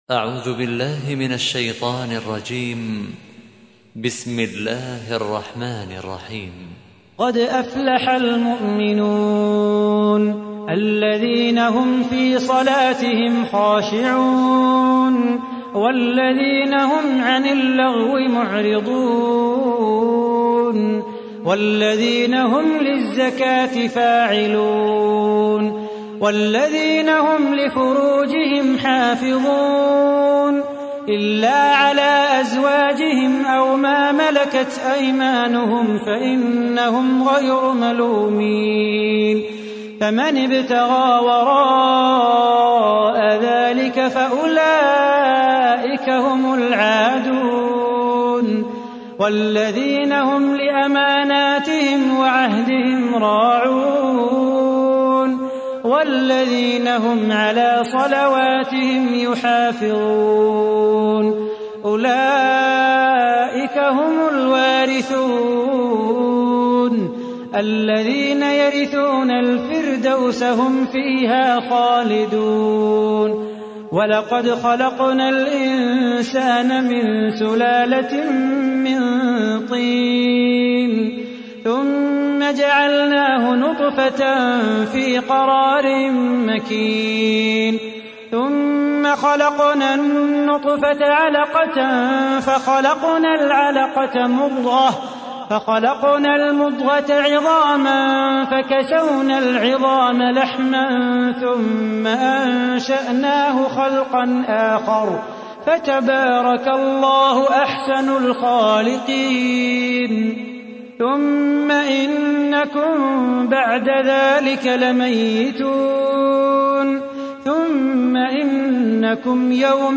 تحميل : 23. سورة المؤمنون / القارئ صلاح بو خاطر / القرآن الكريم / موقع يا حسين